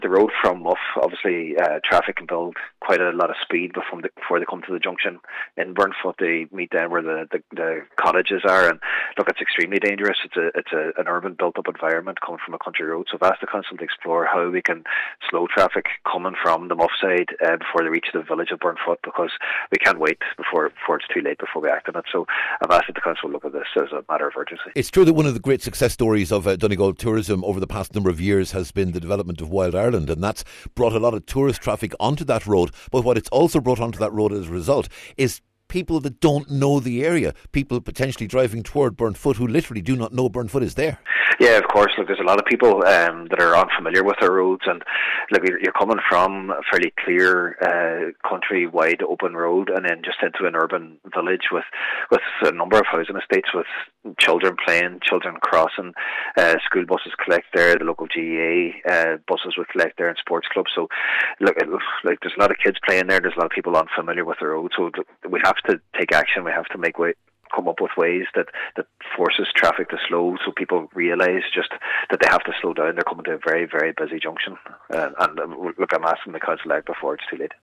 Cllr Murray says it’s vital that the council examine ways of slowing the traffic as it enters Burnfoot………